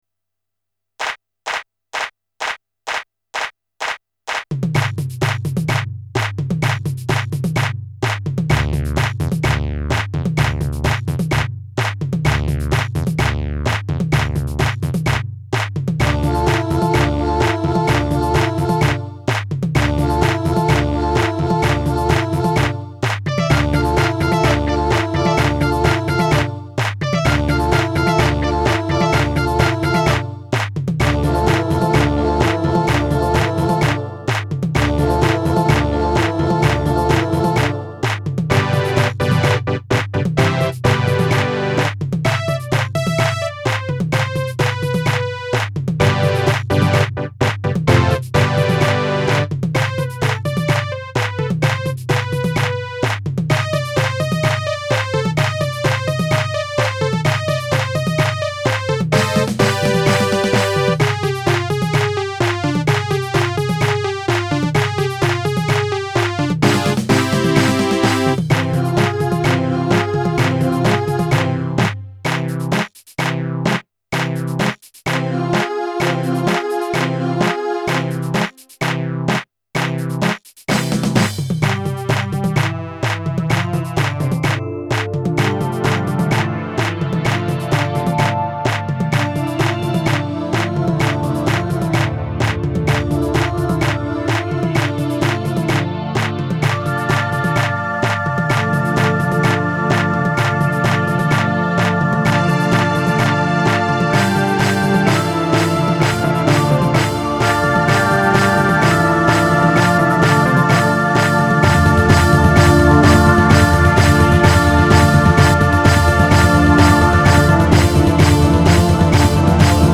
Tuneful and catchy.